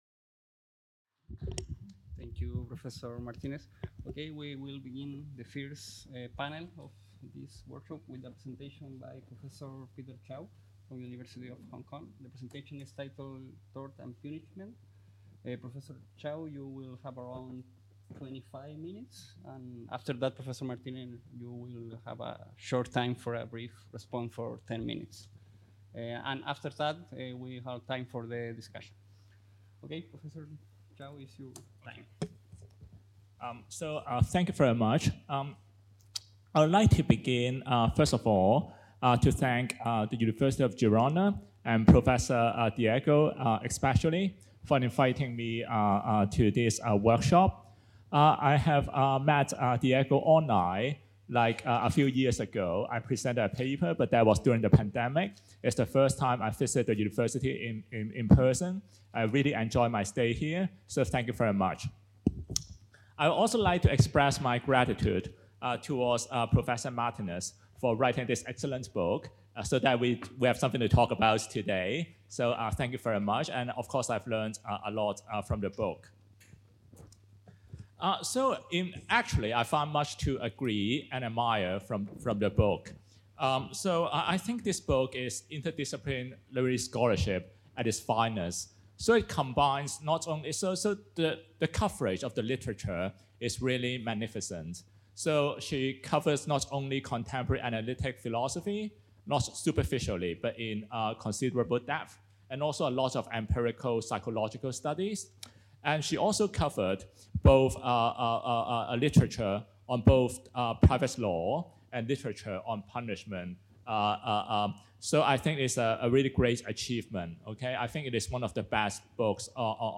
The Chair of Legal Culture organizes the 9th workshop on the philosophy of private law